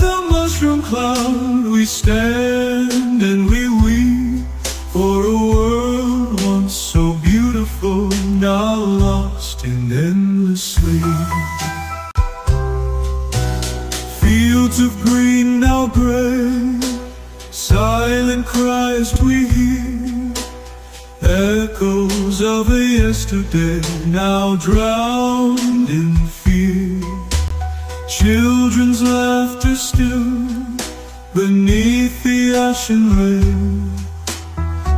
# Jazz